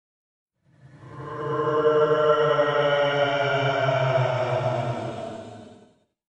Мемный бруэ в замедленном или растянутом варианте